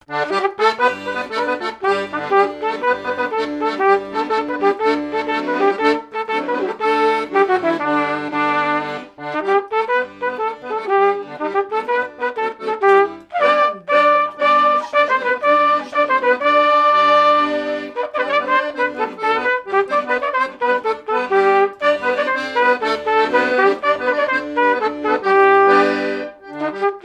danse du balais
airs de danses issus de groupes folkloriques locaux
Pièce musicale inédite